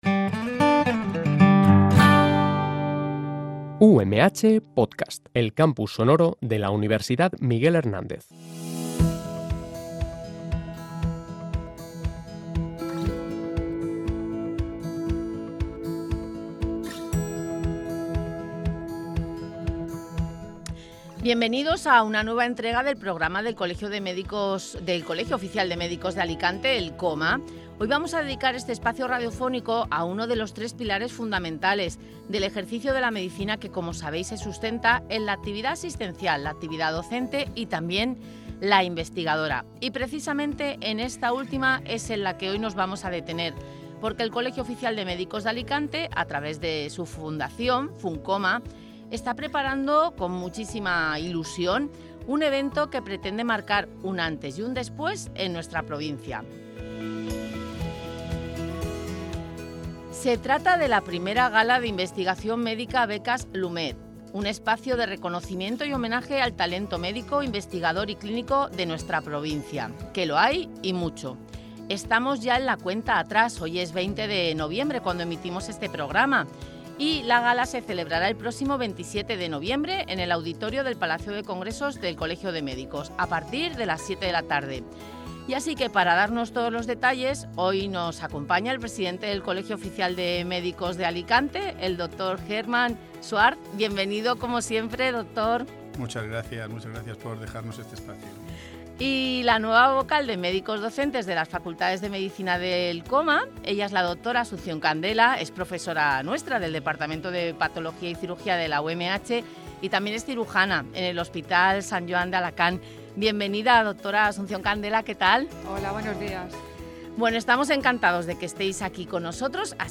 fueron los protagonistas del programa de radio del COMA en la UMH.